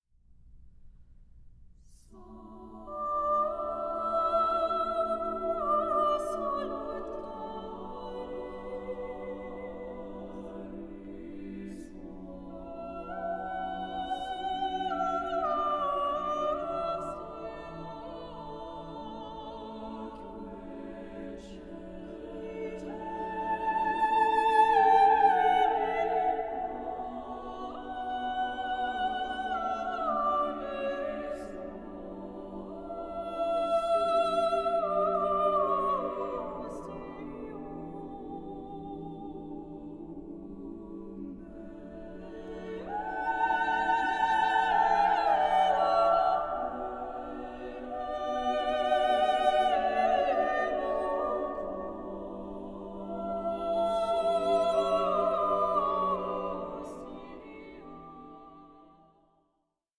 contemporary Scandinavian and Baltic choral music